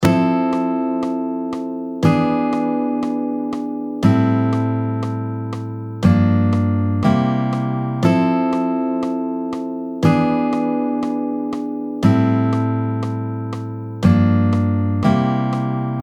最近の楽曲でもよく耳にする進行でとても人気な進行ですね。おしゃれなコード進行です。
ⅣM7-Ⅲ7-Ⅵm7-Ⅴm7-Ⅰ7音源
43651進行.mp3